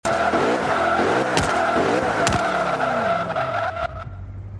Index of /server/sound/vehicles/lwcars/merc_slk55
slowdown_slow.wav